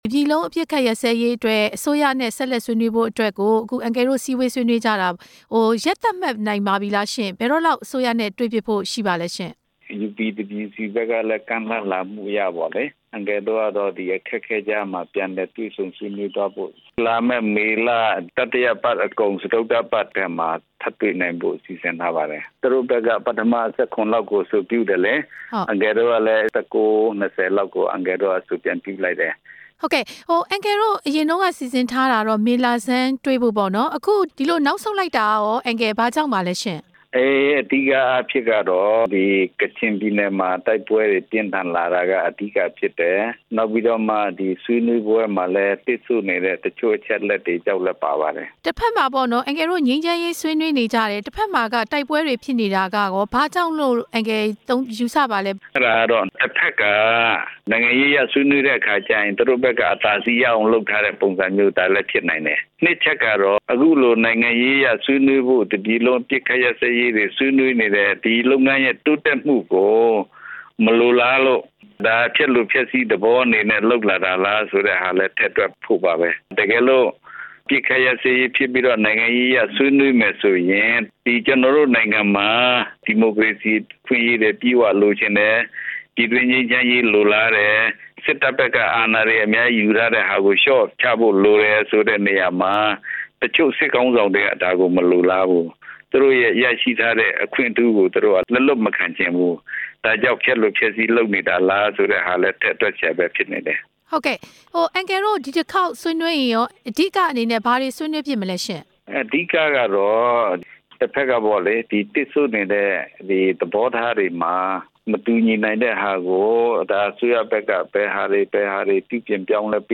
မေးမြန်းချက်